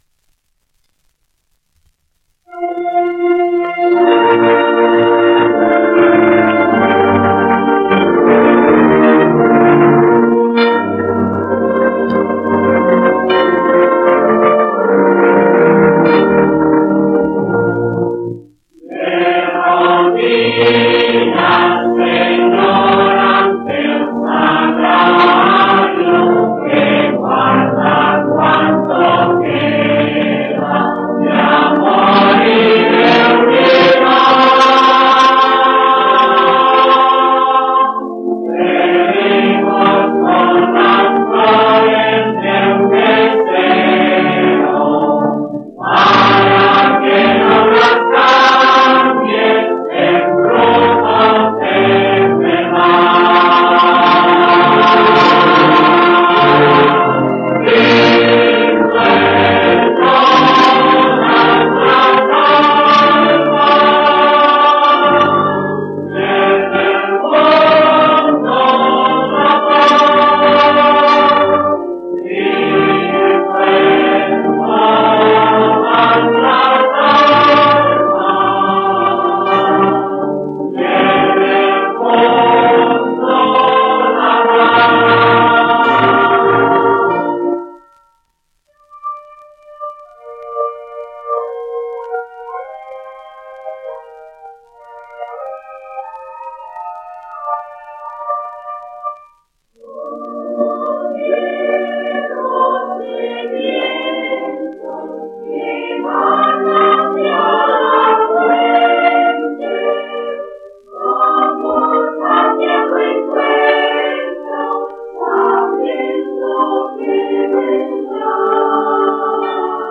1 disco : 78 rpm ; 25 cm